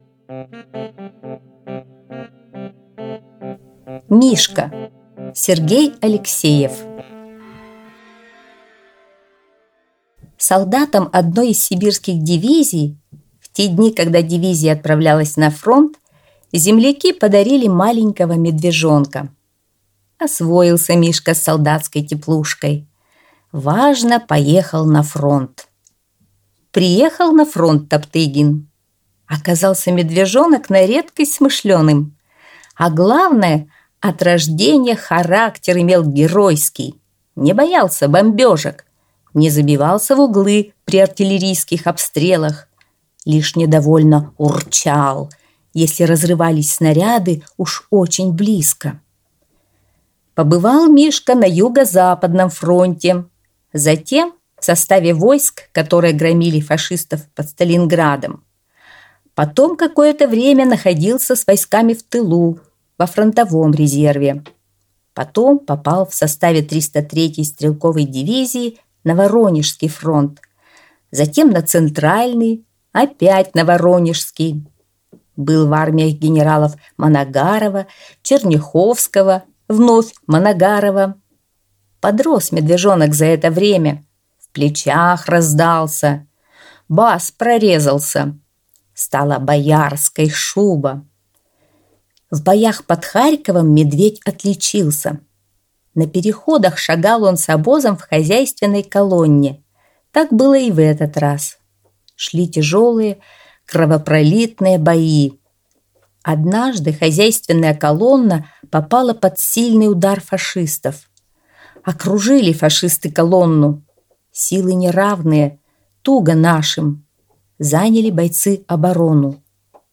Аудиорассказ «Мишка»